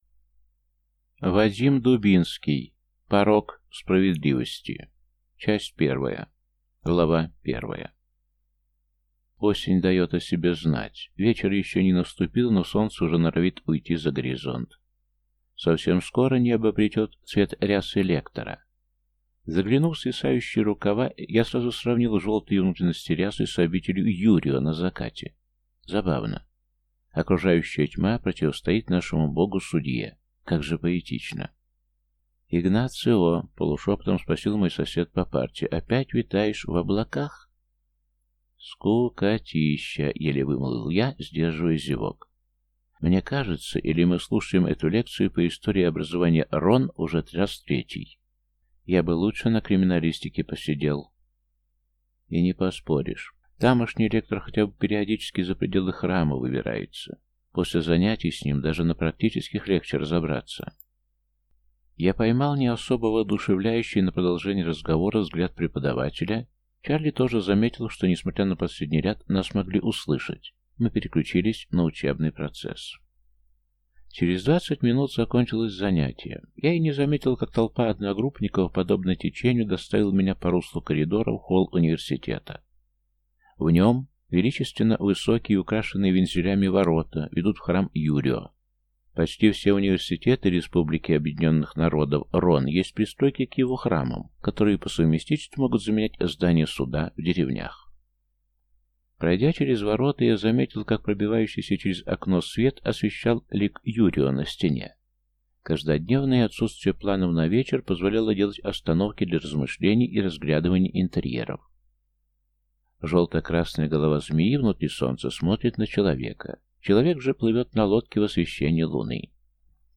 Аудиокнига Порок справедливости | Библиотека аудиокниг
Прослушать и бесплатно скачать фрагмент аудиокниги